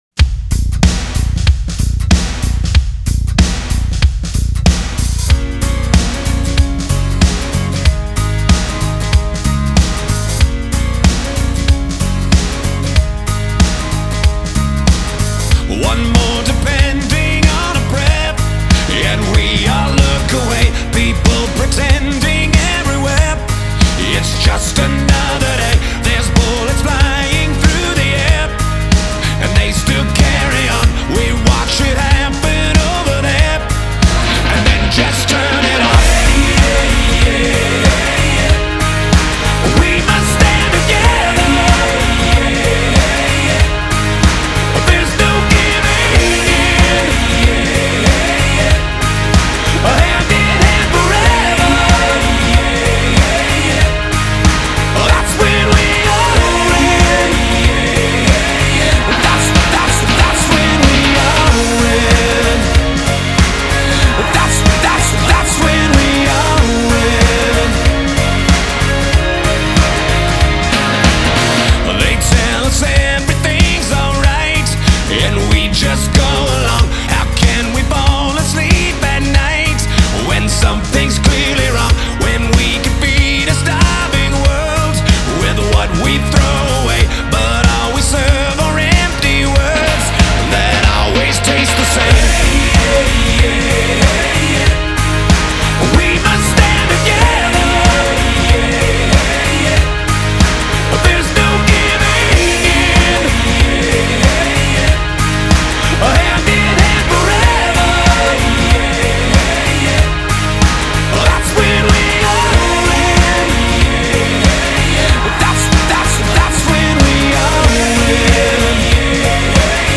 سبک: راک